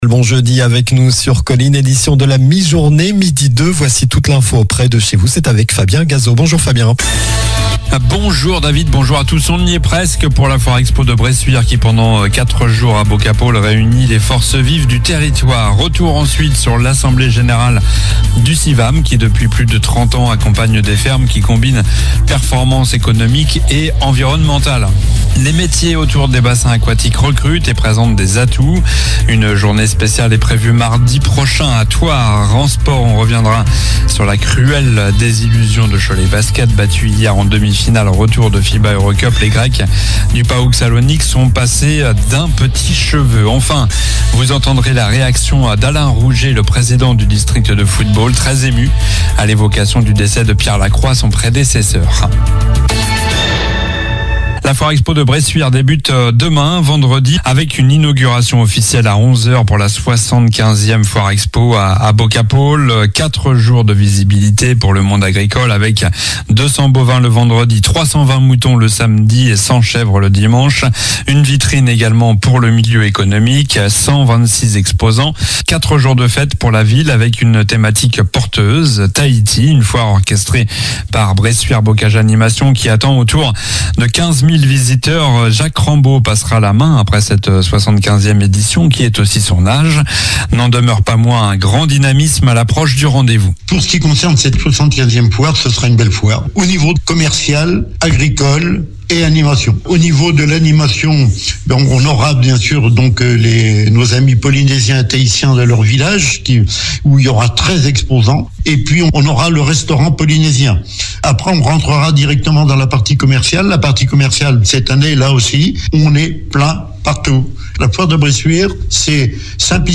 Journal du jeudi 03 avril (midi)